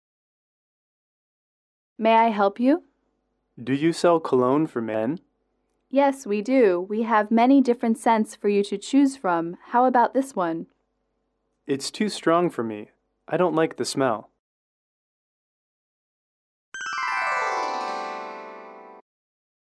英语主题情景短对话02-2：男士买香水（MP3）